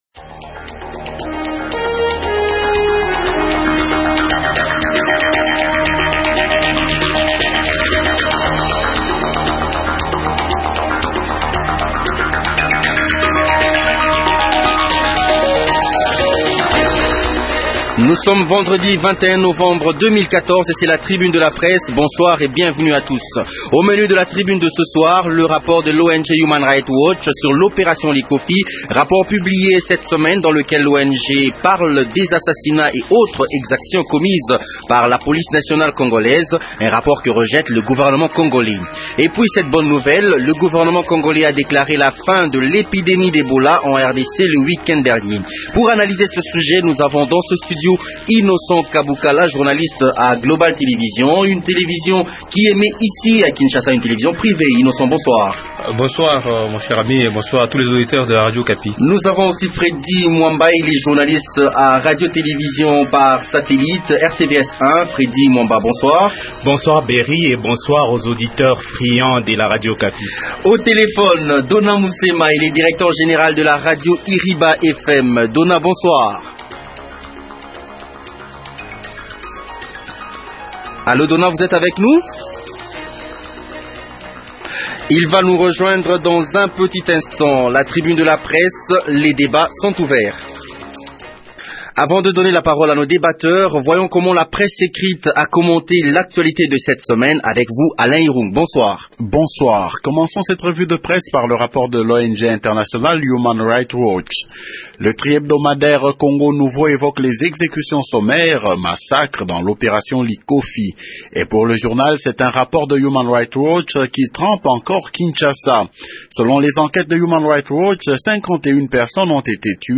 Deux thèmes sont au menu de la Tribune de la presse de ce jour: